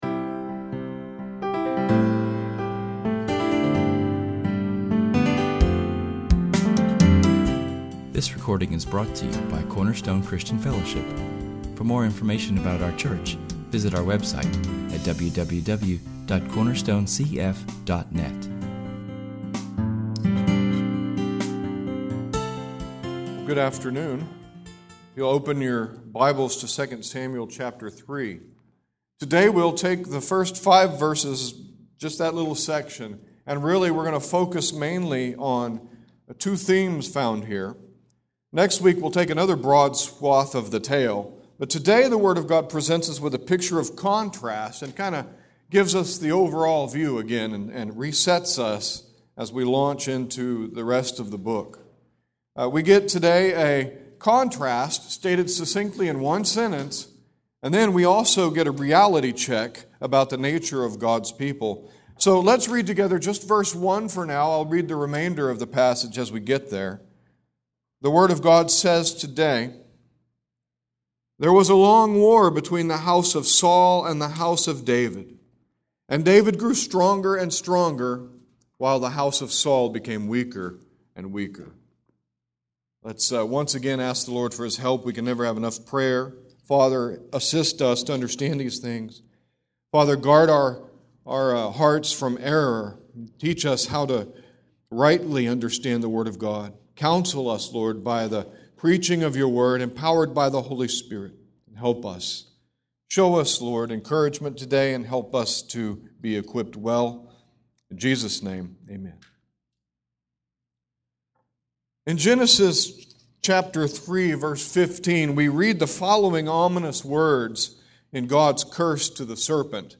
Our sermon is entitled There Was a Long War and is taken from [esvignore]2 Samuel 3:1-5[/esvignore]. In this message we will examine the frustration of living in a world where the Kingdom of God progresses at what seems to us to be a snail’s pace.